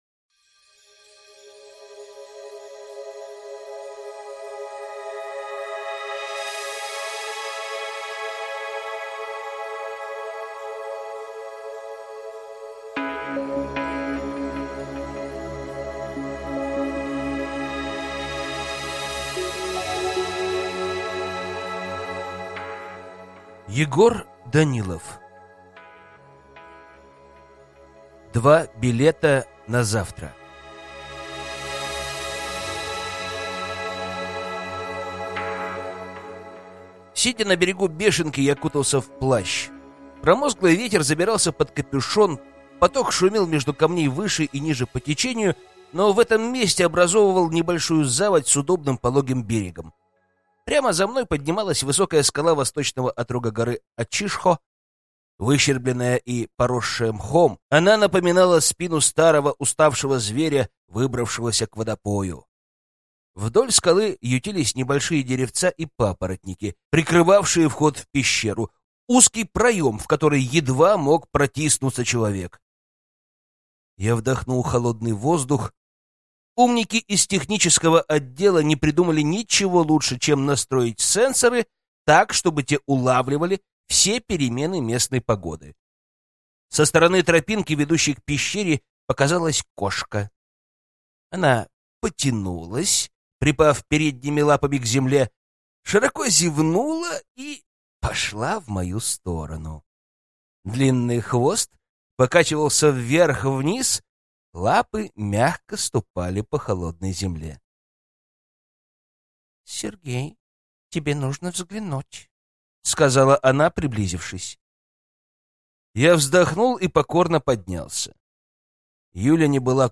Аудиокнига Два билета на завтра | Библиотека аудиокниг
Прослушать и бесплатно скачать фрагмент аудиокниги